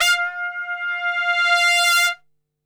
F 3 TRPSWL.wav